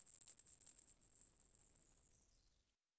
NormalExplosion.wav